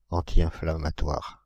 Ääntäminen
Synonyymit antiphlogistique Ääntäminen France (Île-de-France): IPA: /ɑ̃.ti.ɛ̃.fla.ma.twaʁ/ Haettu sana löytyi näillä lähdekielillä: ranska Käännöksiä ei löytynyt valitulle kohdekielelle.